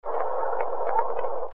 After filtering the noise (using noise canceling in Movie Edit Pro) The whistle became fairly clear, both audibly & visually (next 2  waveforms).
"Whistle" section after filtering
govdock5whistle.mp3